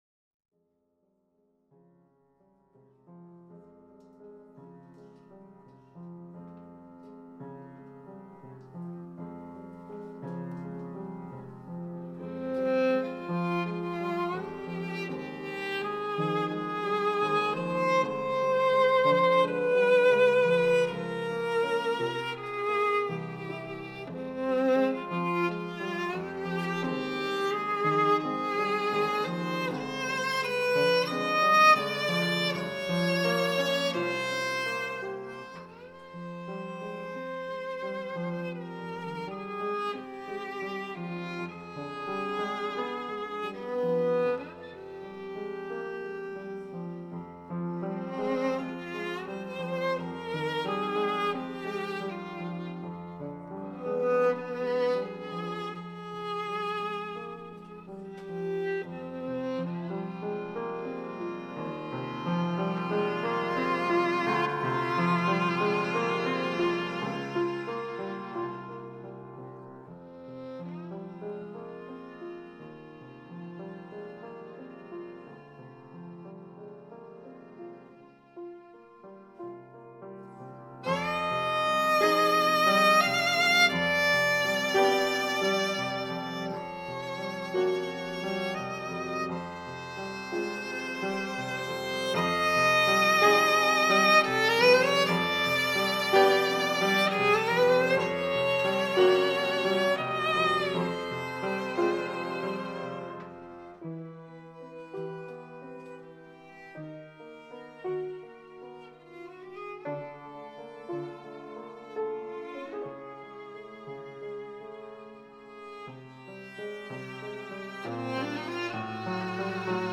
Просто оставлю здесь upd zoom h2.